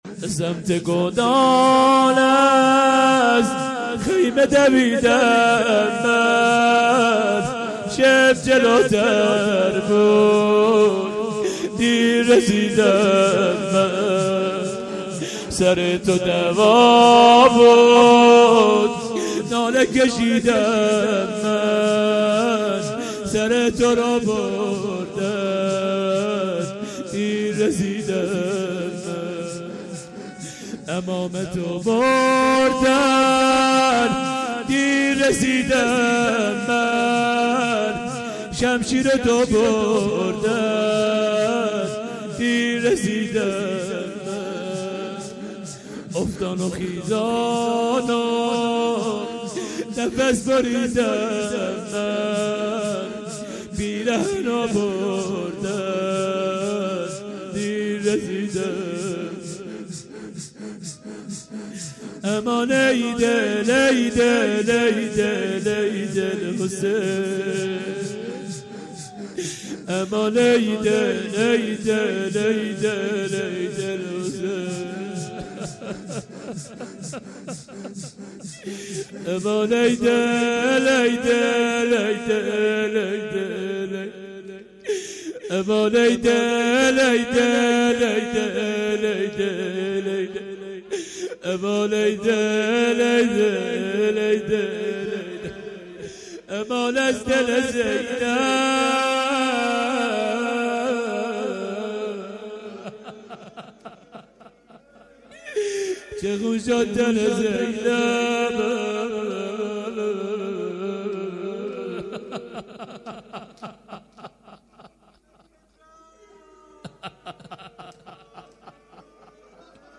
شور گودال | به سمت گودال از